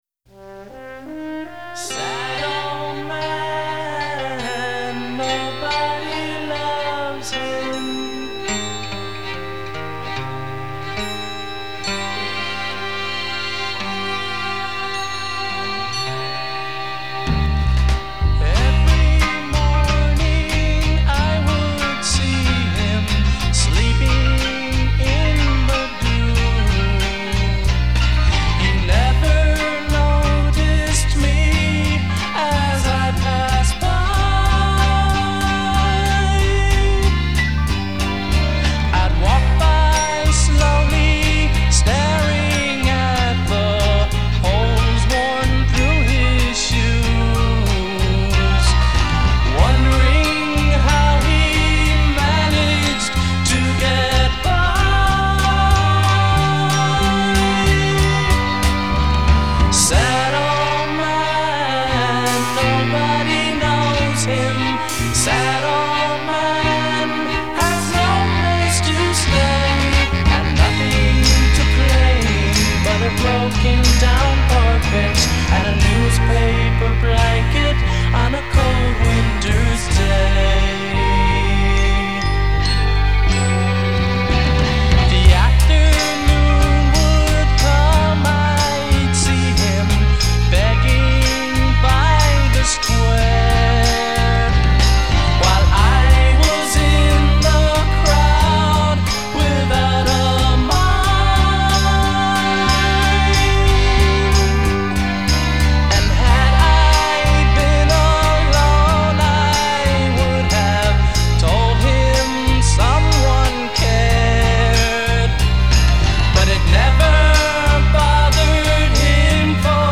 Главное - живые инструменты.